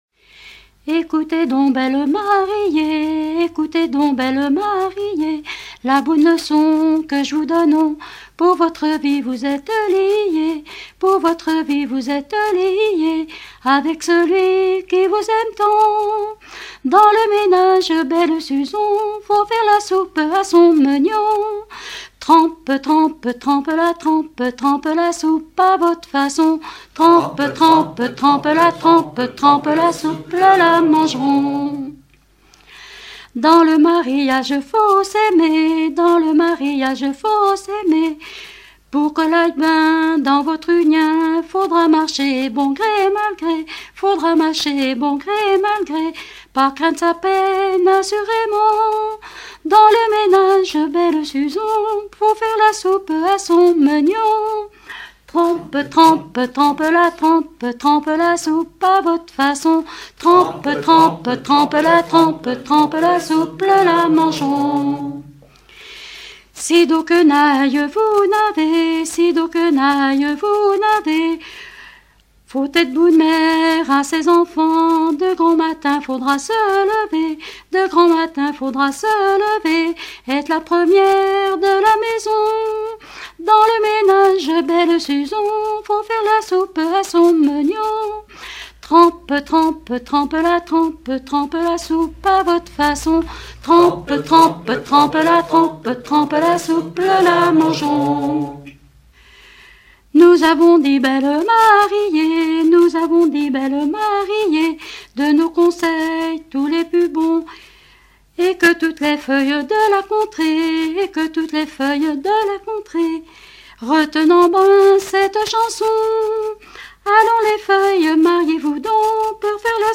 circonstance : fiançaille, noce ;
Genre énumérative
Pièce musicale éditée